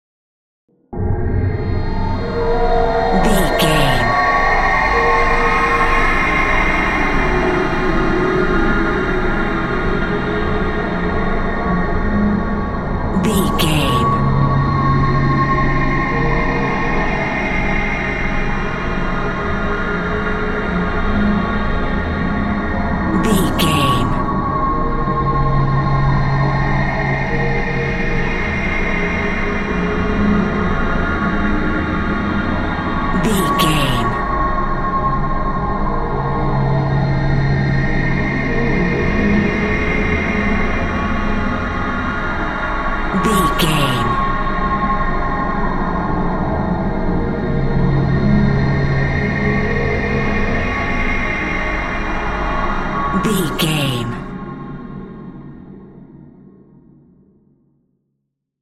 Atonal
scary
tension
ominous
dark
haunting
eerie
ambience